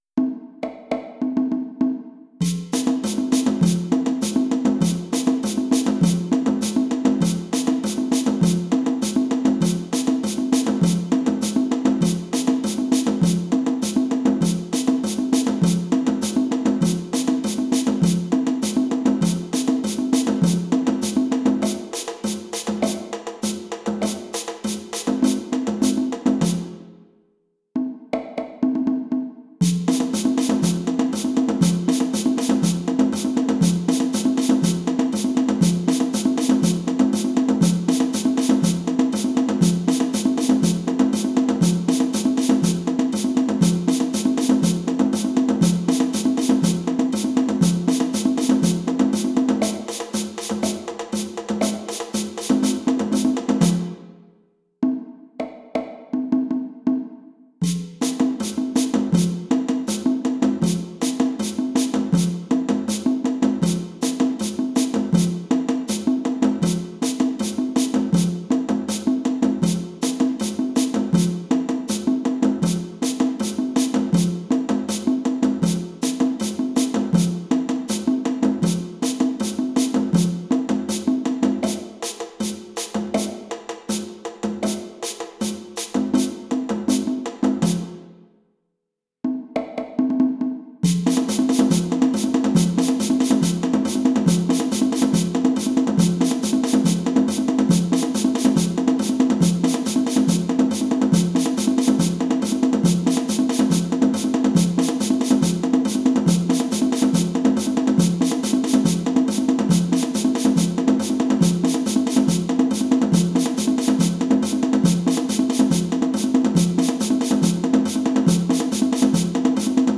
Lead drum + ensemble drum part (audio)